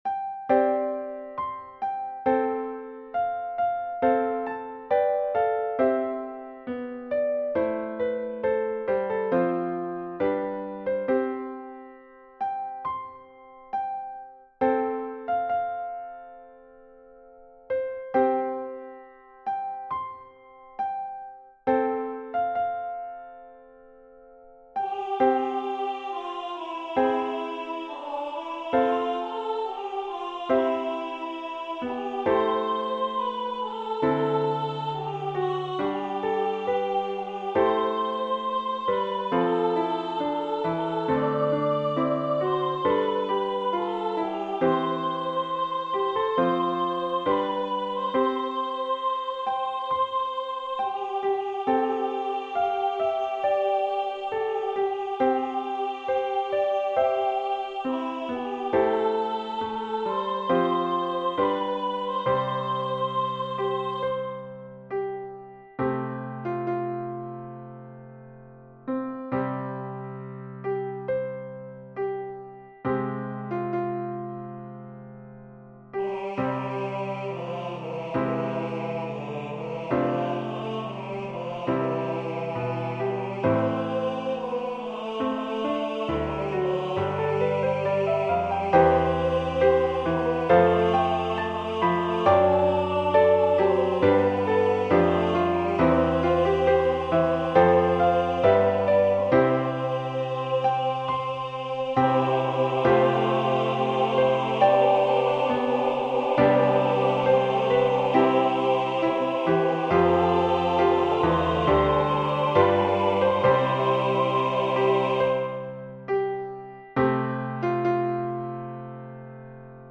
It Is Well with My Soul--Hymn #1003 SATB with Piano Accompaniment.
Voicing/Instrumentation: SATB We also have other 36 arrangements of " It is Well with My Soul ".